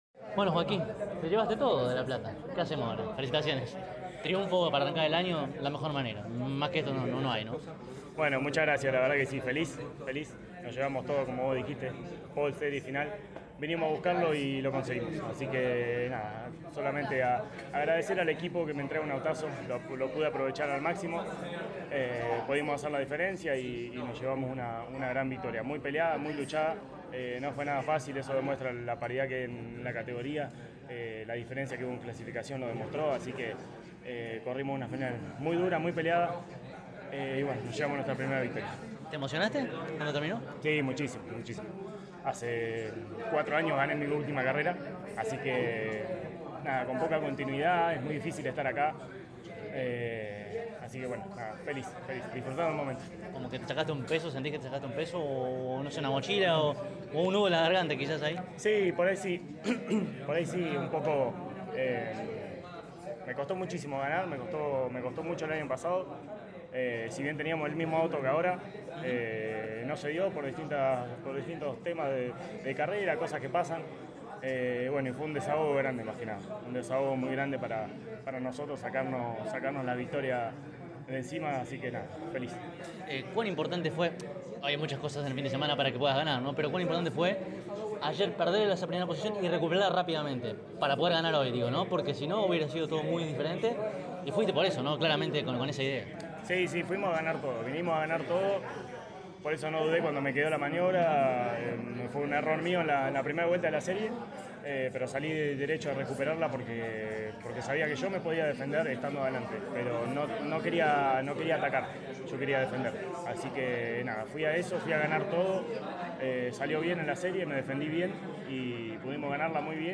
CÓRDOBA COMPETICIÓN estuvo allí presente y dialogó con los protagonistas más importantes al cabo de cada una de las finales.